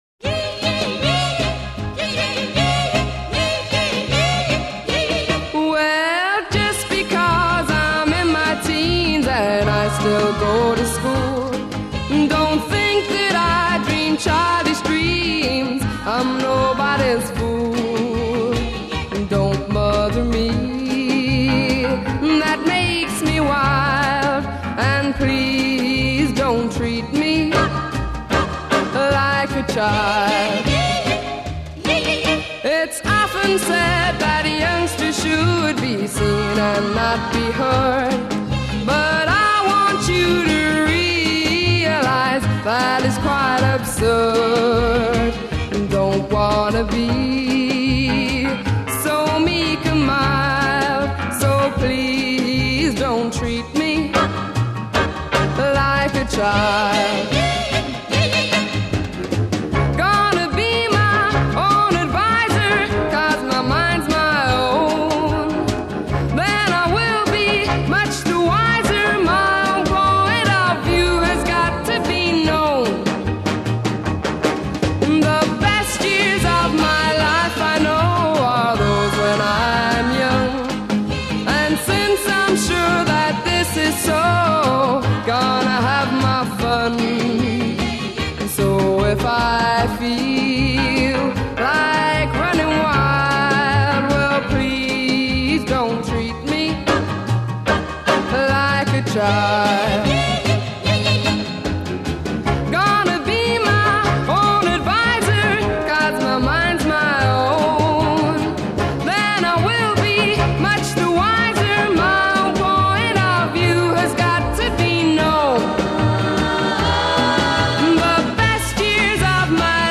Recorded 16 January 1961 in EMI Recording Studios, London.